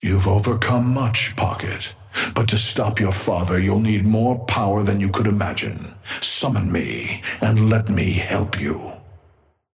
Amber Hand voice line - You've overcome much, Pocket, but to stop your father you'll need more power than you could imagine.
Patron_male_ally_synth_start_01.mp3